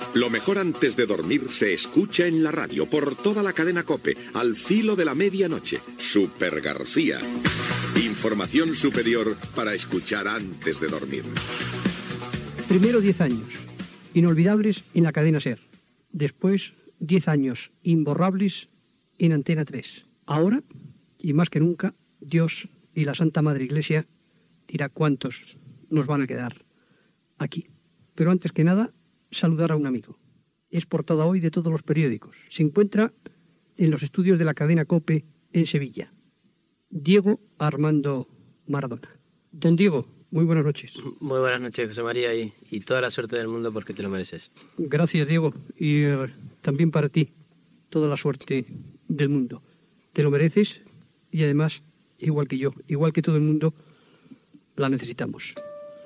Inici del primer programa a la COPE. Careta del programa i presentació del jugador Diego Armando Maradona
Esportiu